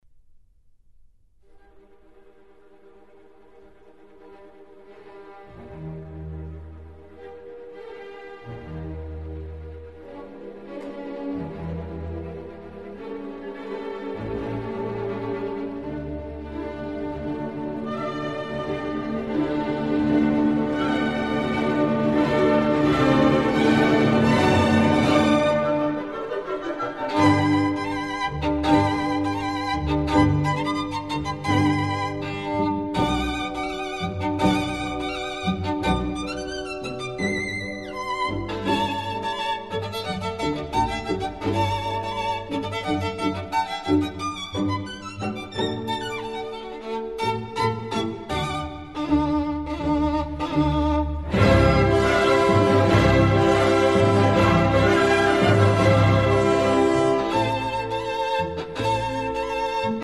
Max Bruch - Concerto no. 1 in G minor, op. 26 - 3. Finale